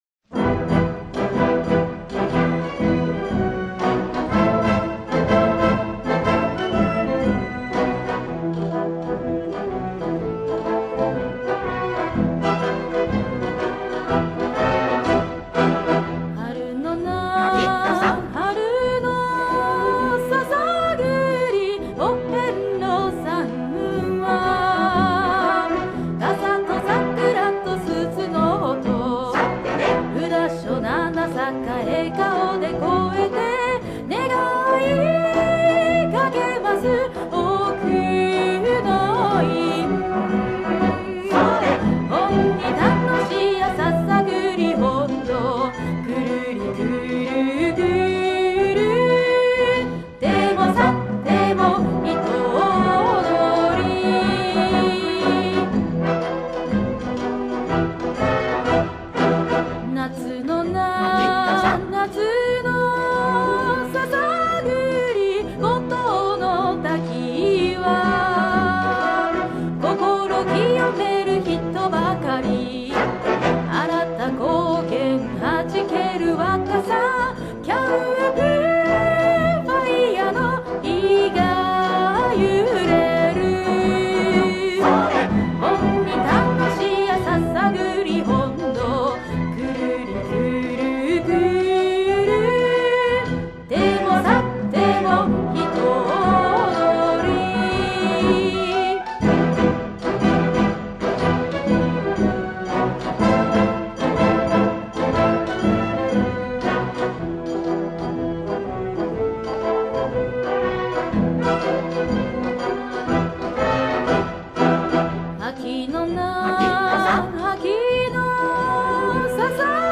テンポを変えた曲を用意しています。
2.動画と同じ速さ（60bpm）
sasa_60bpm.mp3